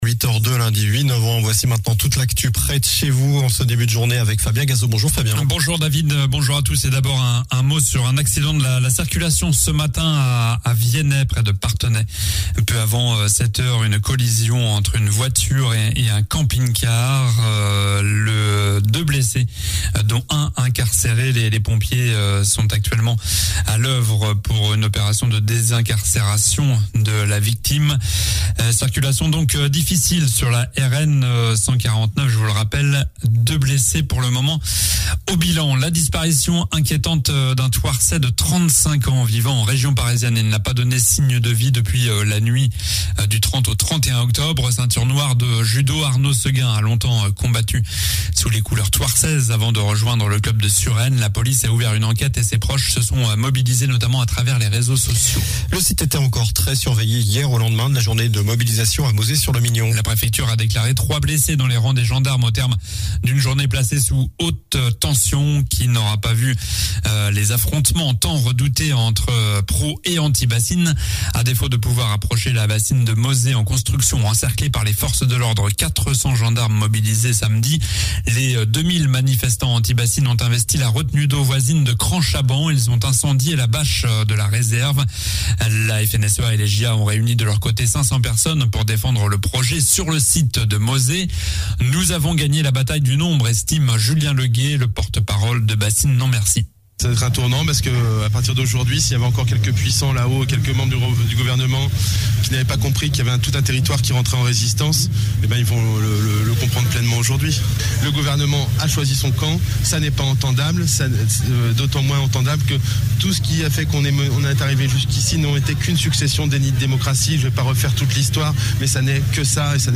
Journal du lundi 08 novembre (matin)